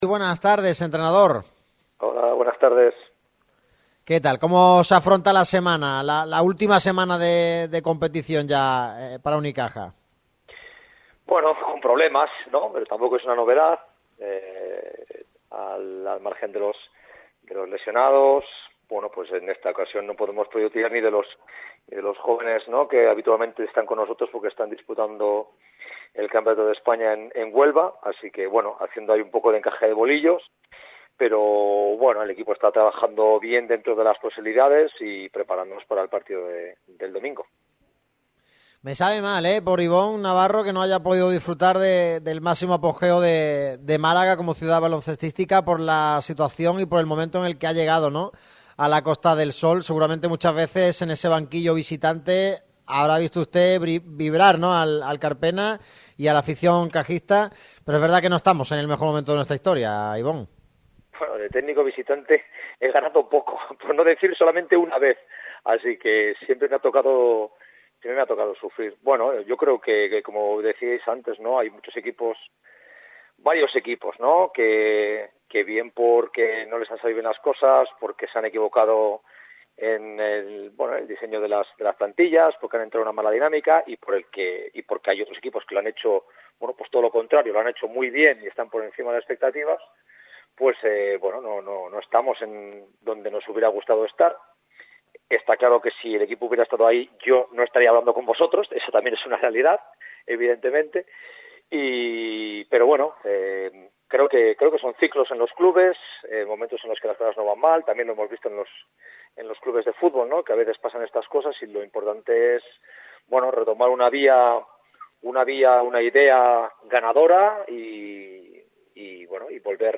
Este jueves nos acompaña Ibon Navarro para repasar toda la actualidad de Los Guindos. El vitoriano ha caído de pie en Málaga desde que llegara en febrero, con la incógnita de si liderará el nuevo proyecto este verano.